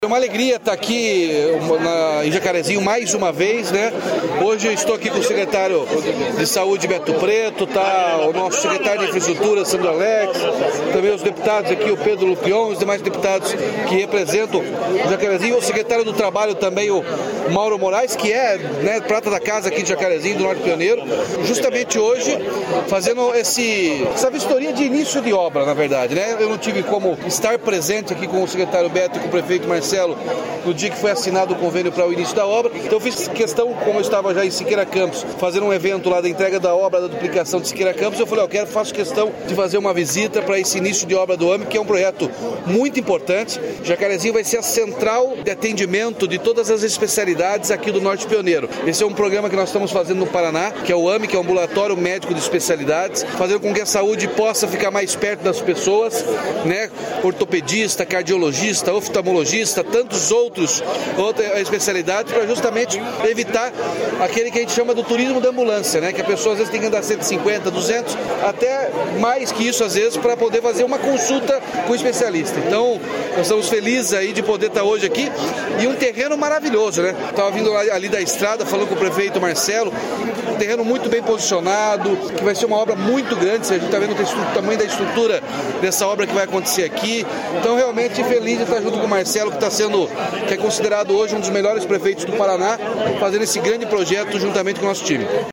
Sonora do governador Ratinho Junior sobre a vistoria às obras do AME de Jacarezinho